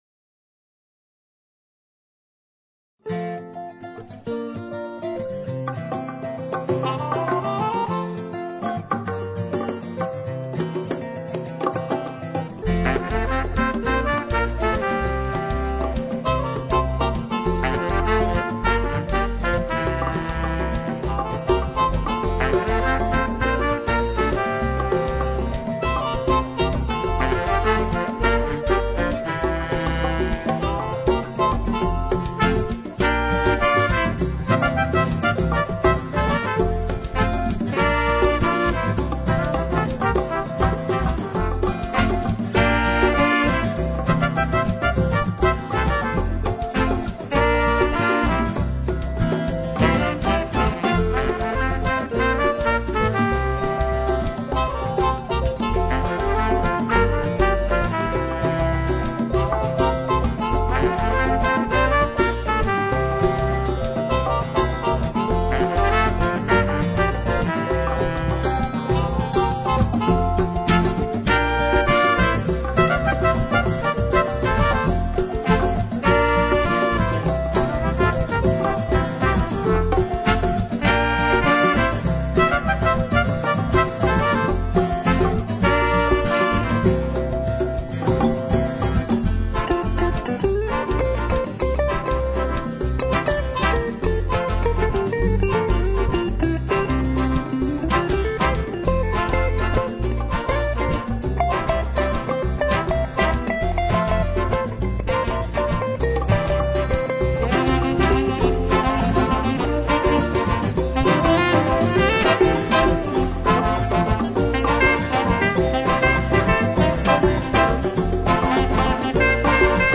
A hi-life number is a good idea.